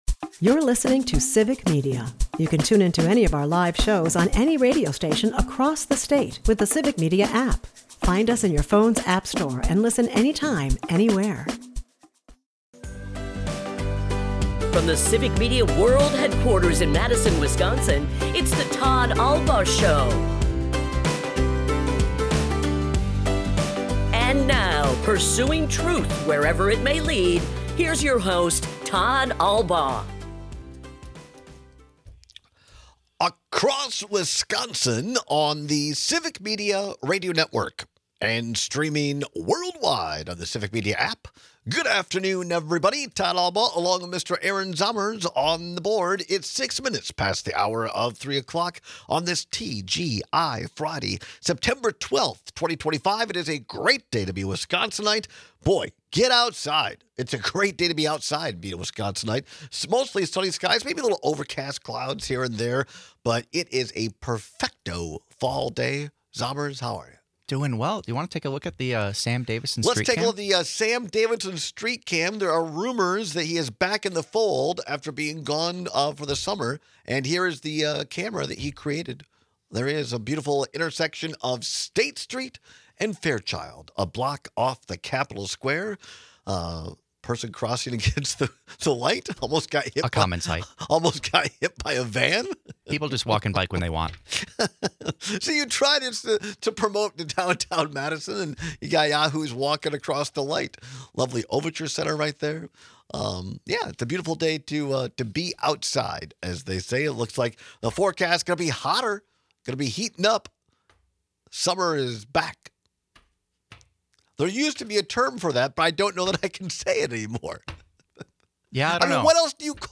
We take your calls and texts on whether it’s worse to do too much or nothing at all.&nbsp